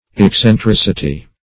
\Ex`cen*tric"i*ty\